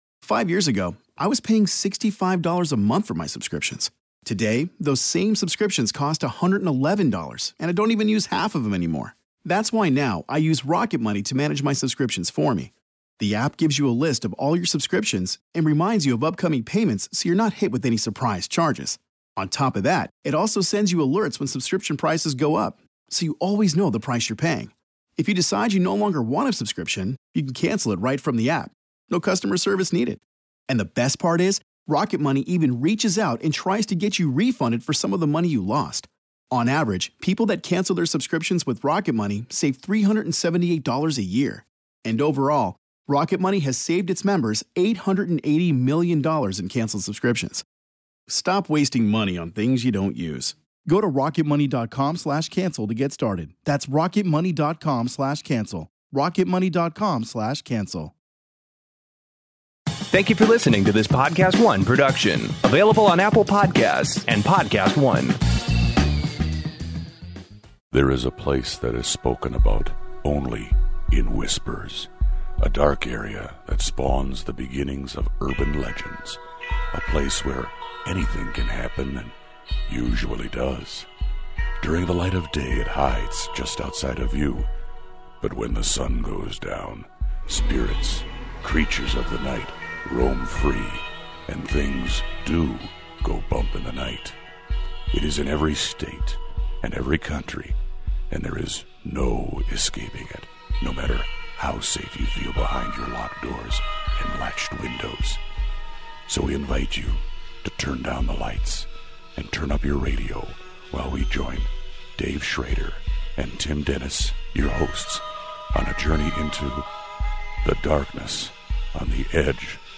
BEYOND the DARKNESS Paranormal Radio Show is back with more Wicked Supernatural News & Listeners' Paranormal Encounters! First, a fish delivers a cursed wedding ring to a new owner.